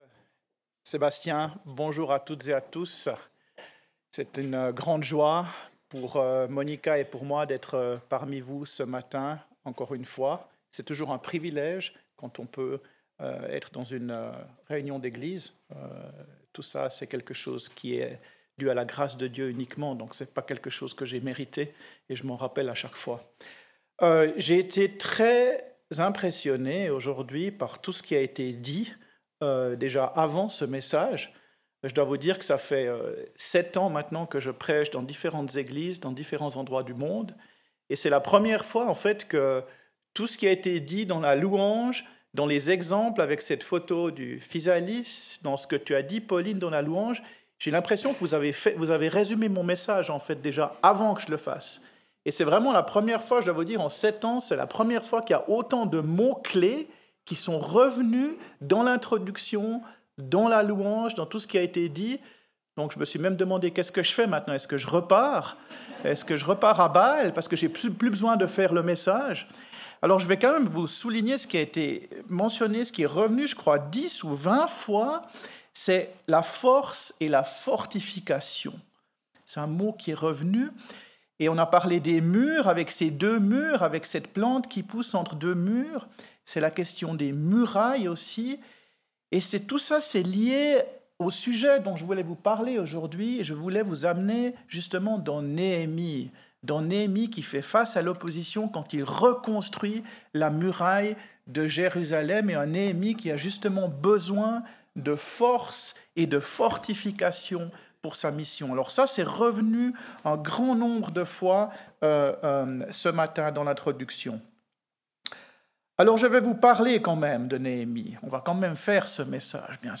À travers l'histoire de Néhémie, cette prédication nous rappelle que nos véritables combats ne sont pas contre des individus, mais de nature spirituelle. En identifiant nos réels adversaires et en restant fortifiés par la prière, nous pouvons accomplir les projets de Dieu malgré l'adversité.